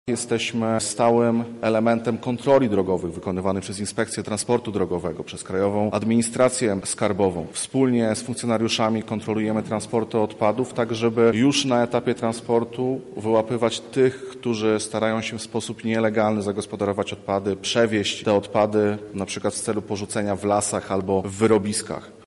• mówi Krzysztof Gołębiewski, główny Inspektor ds. Ochrony Środowiska.